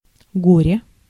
Ääntäminen
France: IPA: [mi.zɛʁ]